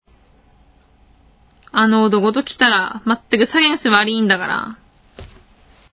全国方言文法データベース 青森県八戸市の原因・理由表現例文集